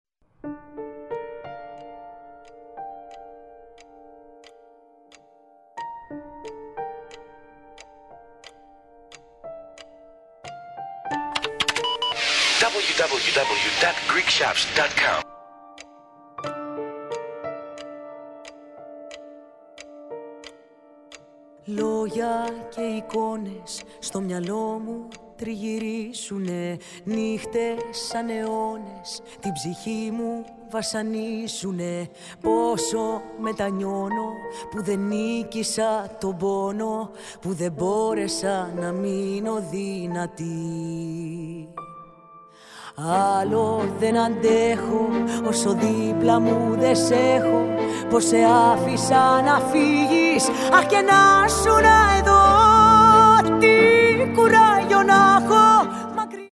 modern laika songs